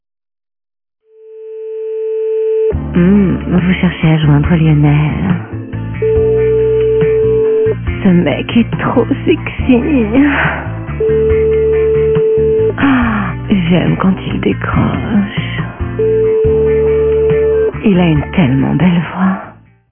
- Personnalisez votre tonalité d’attente et faites patienter vos correspondants autrement ! -